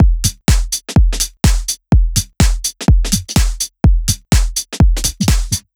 Как сделан этот Drum
Мне, кажется, не микс здесь нужен, а сравнение с другой бочкой. Большинство, как бы вбивают, а у этой иной эффект.